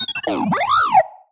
sci-fi_driod_robot_emote_12.wav